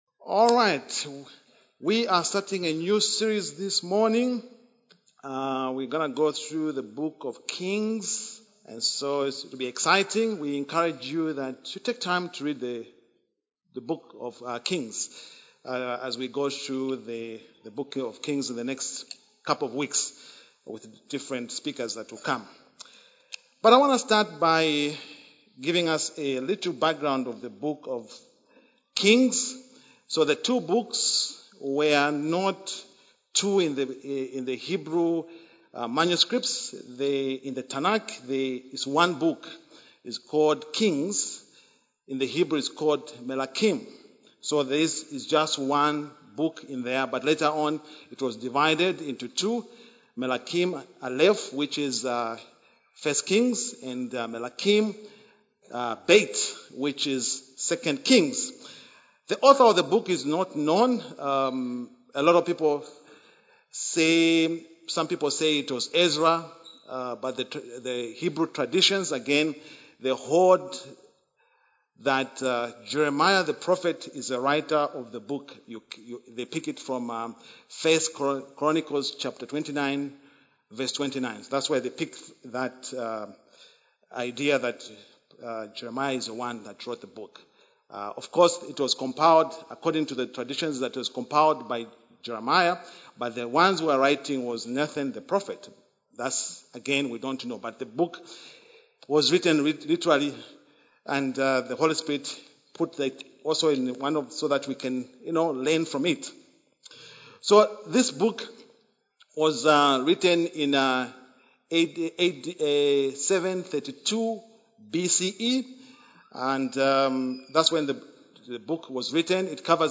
A message from the series "Kings Survey."